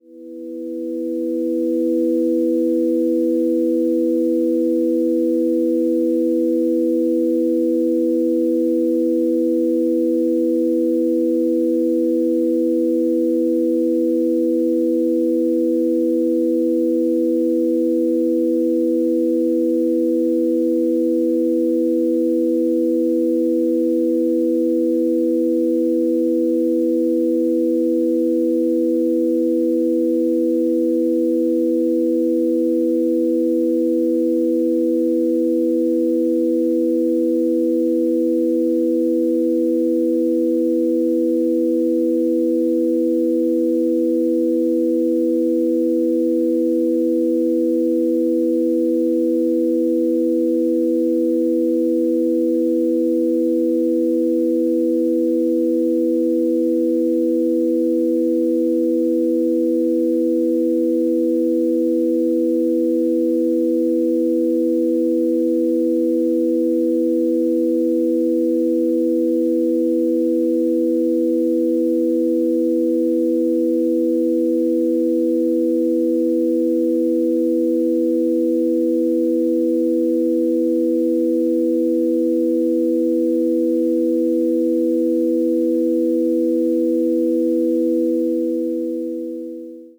Simple ambient loops for testing:
**⚠  NOTE:** Music/SFX are PLACEHOLDERS (simple tones)